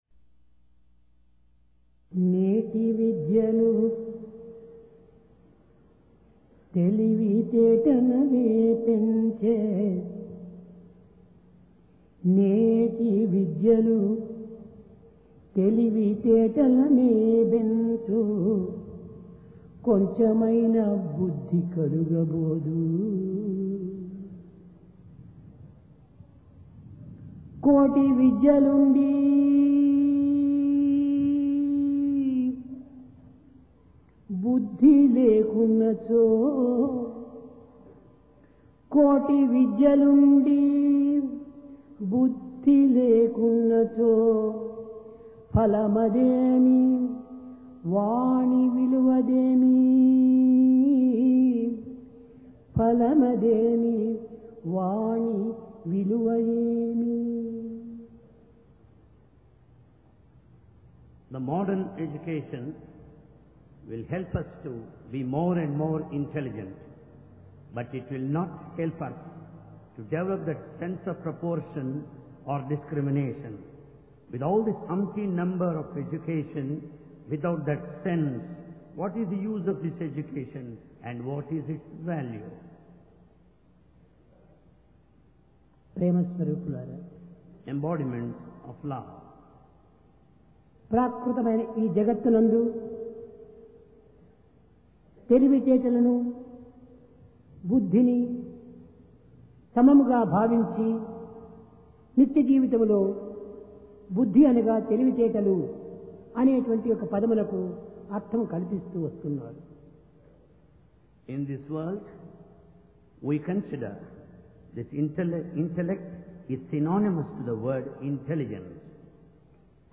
PRASHANTI VAHINI - DIVINE DISCOURSE 21 JUNE, 1996